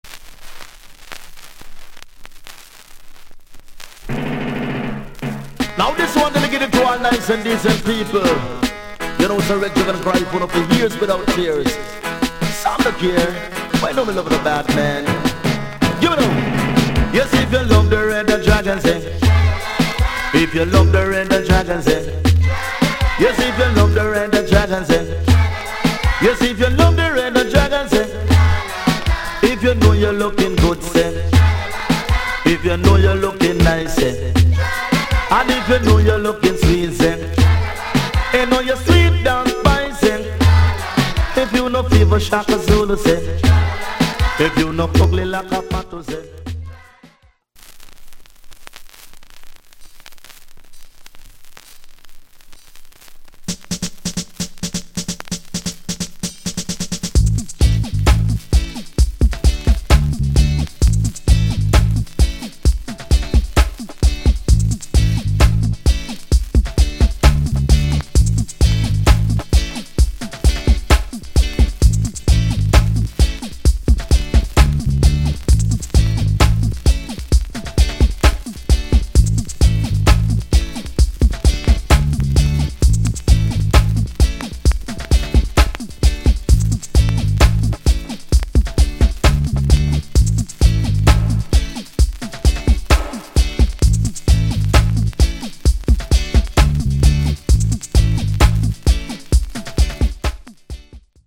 *'88 Big Hit Dance Hall Tune!